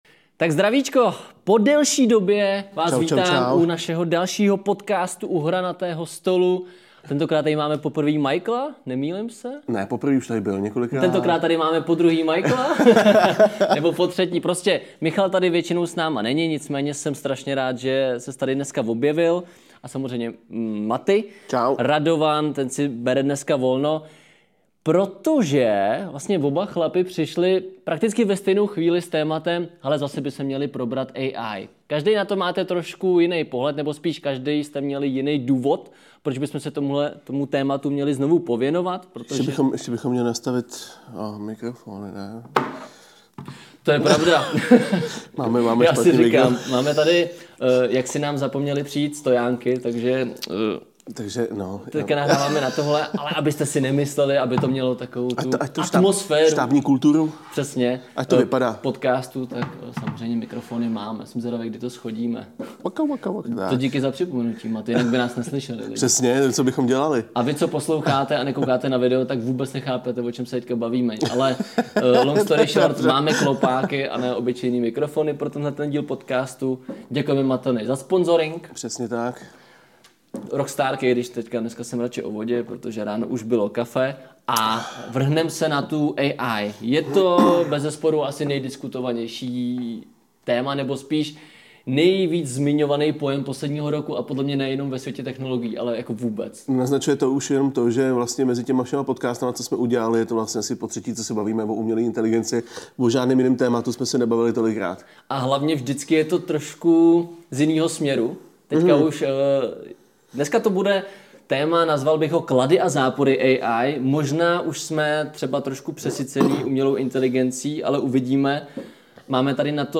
Čtyři mušketýři debatují nad AI - umělou inteligencí. Kde jsme, jaká je v tomhle směru budoucnost a máme se umělé inteligence bát nebo ne?